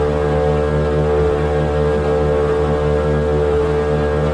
buzzing_generator.wav